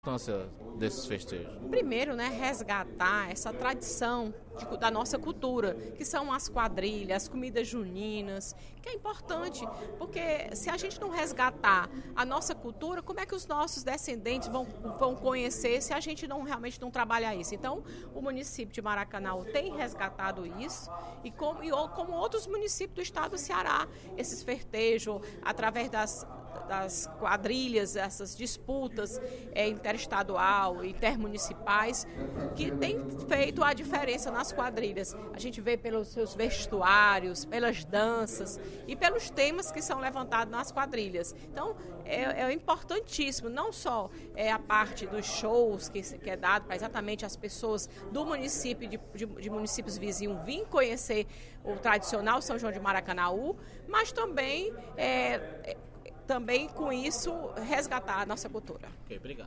A deputada Fernanda Pessoa (PR) destacou, nesta sexta-feira (08/07), no primeiro expediente da sessão plenária da Assembleia Legislativa, os festejos juninos de Maracanaú, no mês passado.